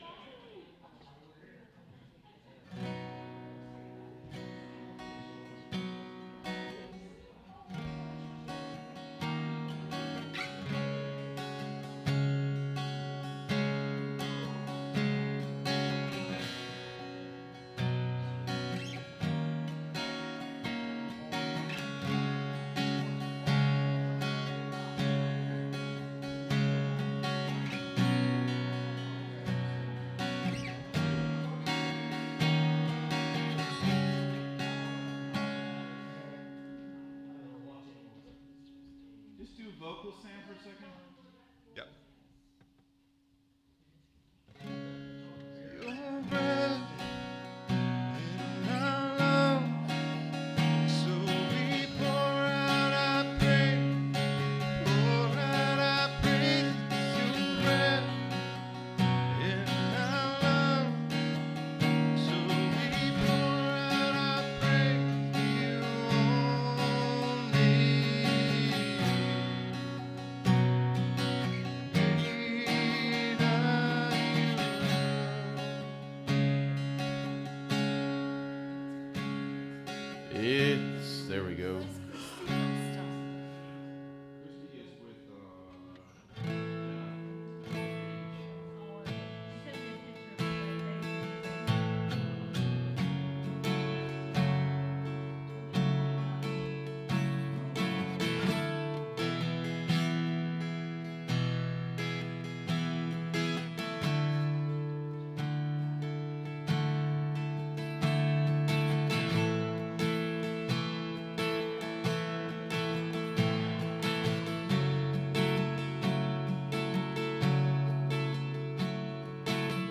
SERMON DESCRIPTION This message explores Romans 6 to show that grace, while it may sound “too good,” is not a permission to sin but the power that ends sin’s rule.